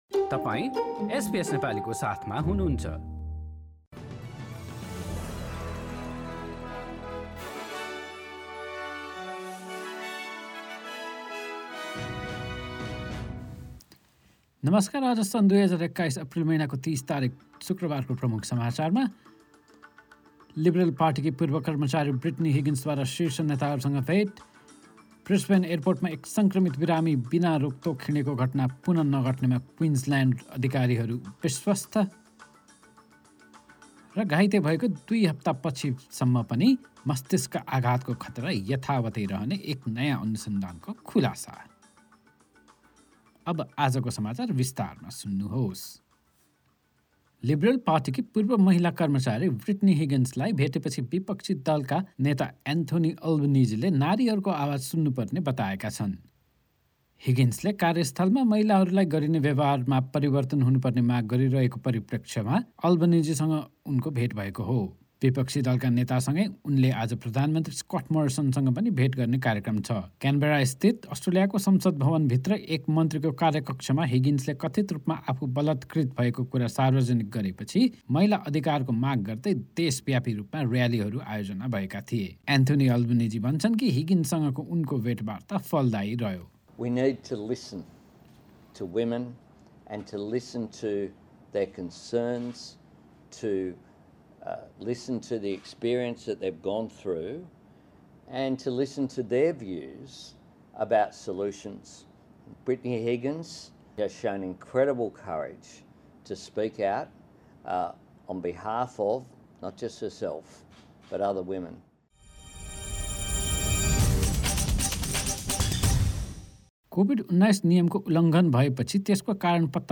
एसबीएस नेपाली अस्ट्रेलिया समाचार: शुक्रवार ३० अप्रिल २०२१